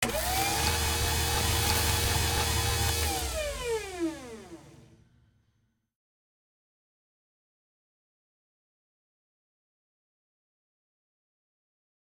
weaponsrecharge.ogg